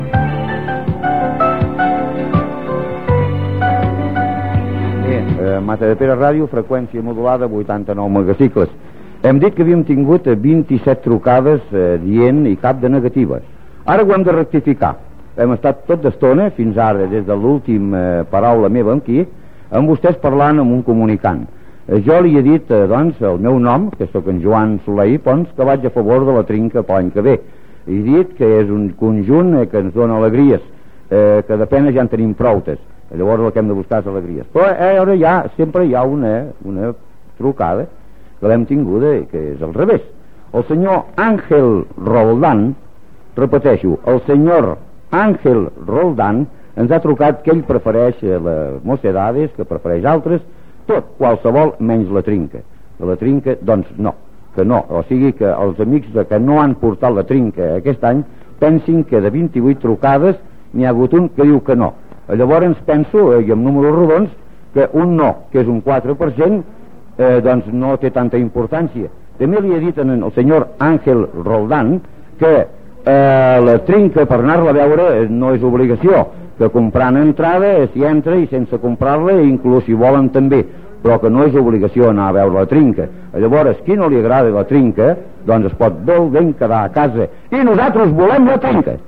Identificació, enquesta sobre la Trinca.
FM
Una de les primeres emissions a l'agost, durant la Festa Major de 1981.